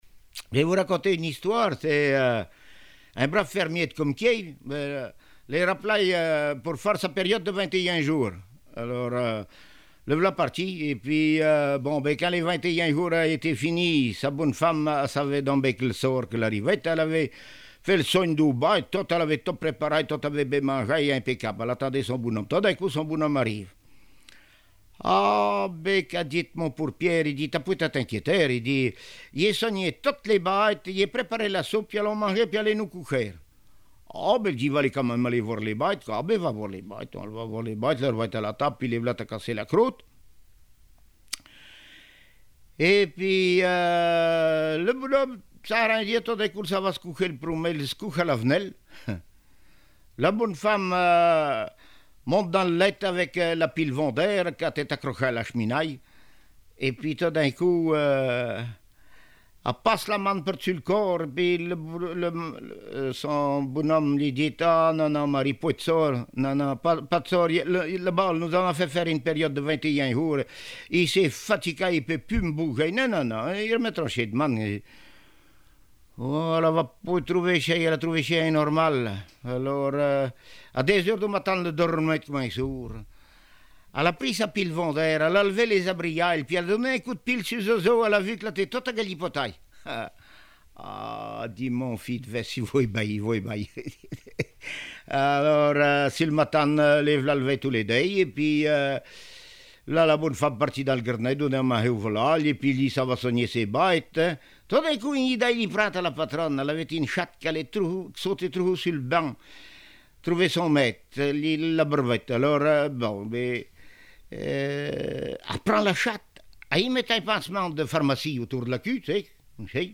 Genre sketch
Enquête Compagnons d'EthnoDoc - Arexcpo en Vendée
Catégorie Récit